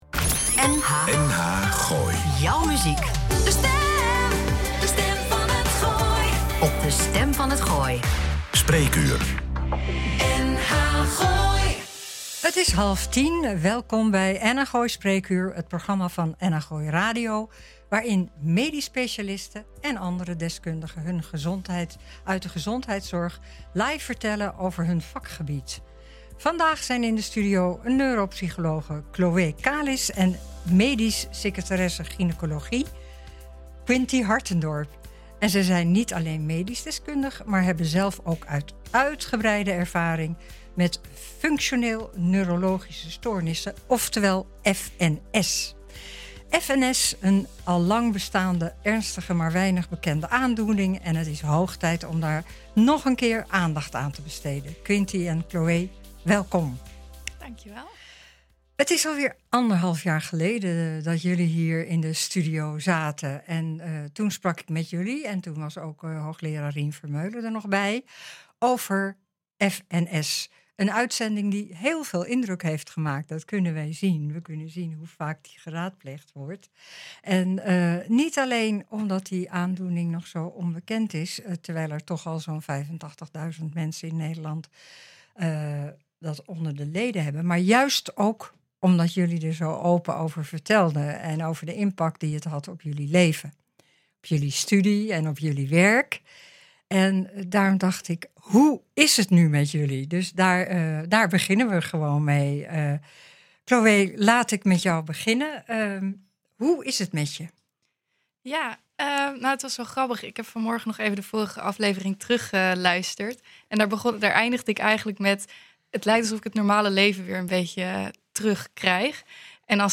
Een open gesprek over leven